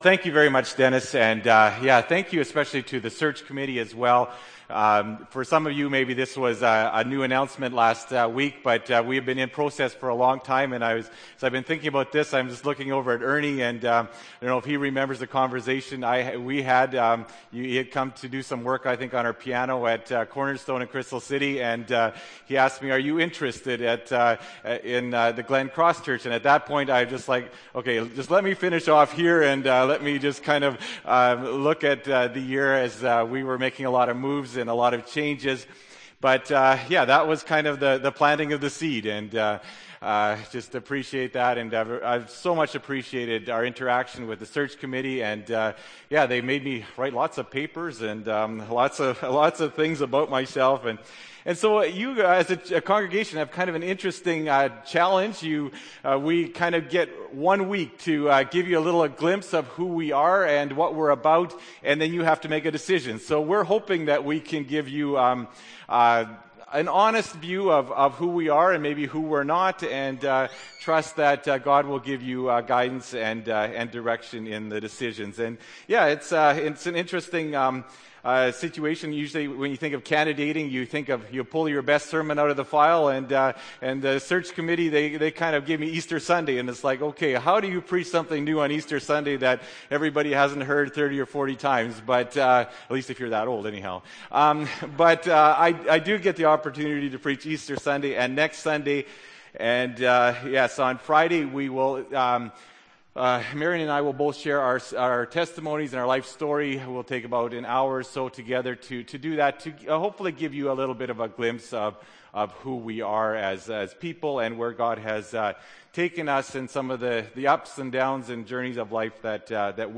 April 8, 2012 – Sermon